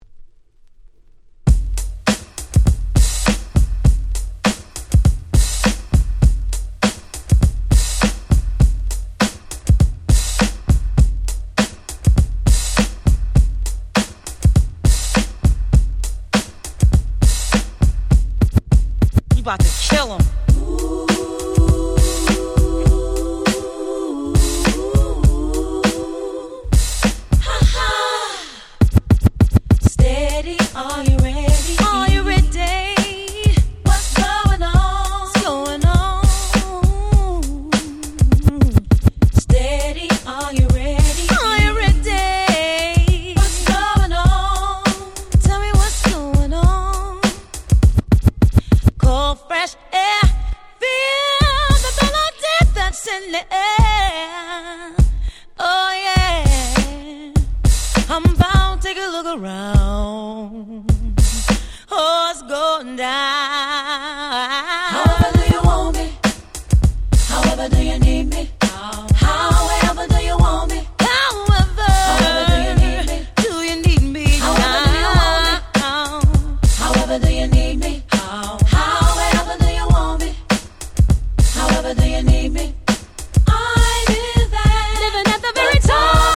オリジナルとは打って変わってプレーンなインピーチBeatから入る超使えるバージョン！！